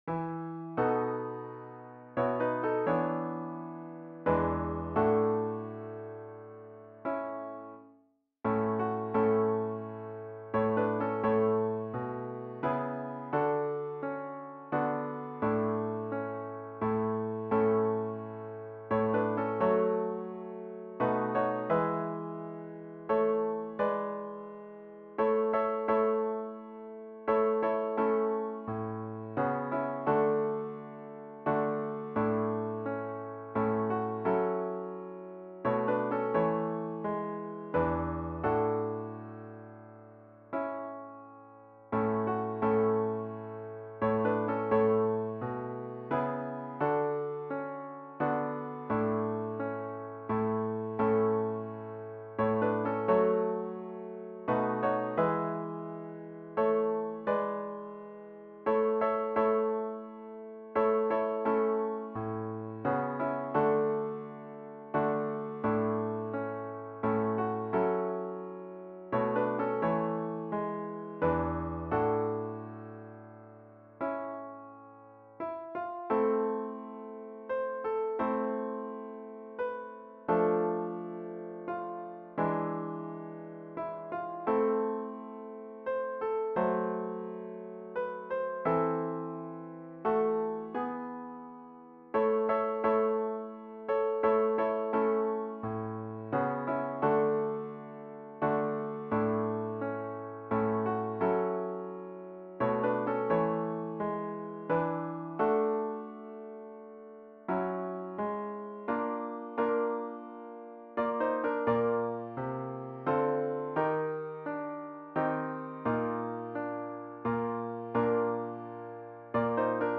A capella arrangements for mixed chorus.
Refreshing harmony with a minor section in third stanza.